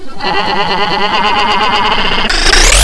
1 channel
snd_21070_Lamb.wav